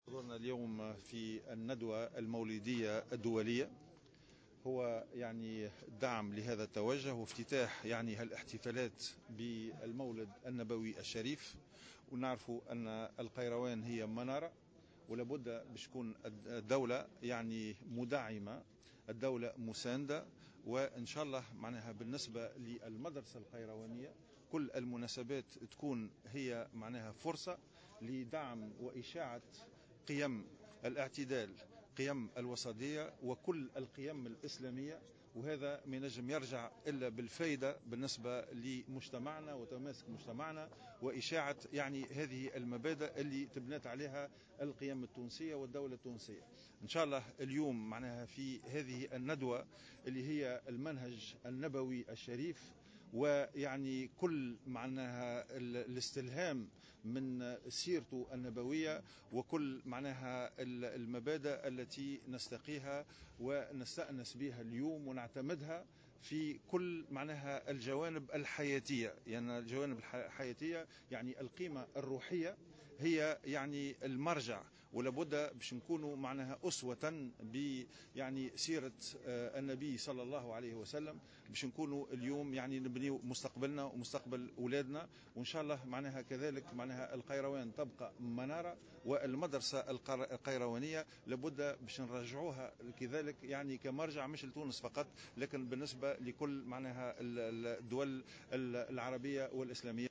وقال وزير الشؤون الدينية بالنيابة، غازي الجريبي في تصريح لمراسل" الجوهرة أف أم" بالجهة إن تنظيم الندوة يأتي استعدادا للاحتفال بالمولد النبوي، مثمنا دور المدرسة القيروانية في نشر القيم الإسلامية الفاضلة والقائمة على التوازن والوسطية والاعتدال.